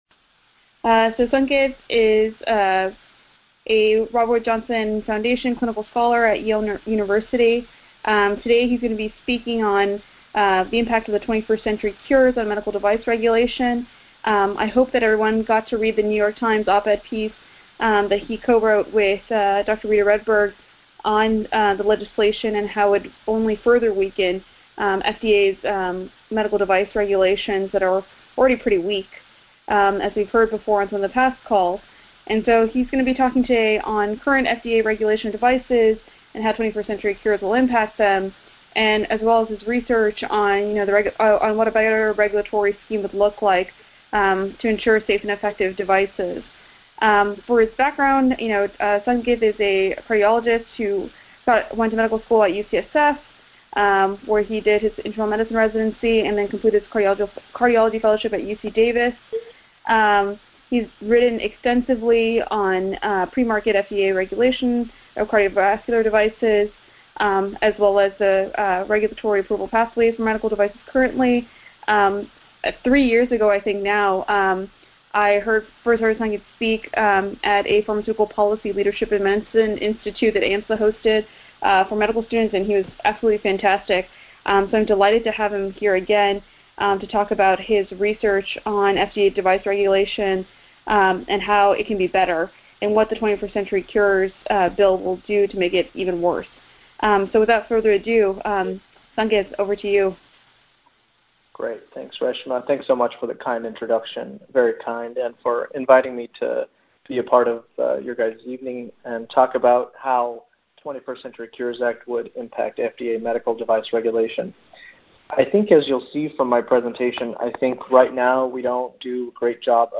NPA FDA Task Force Webinar – September 2015 – National Physicians Alliance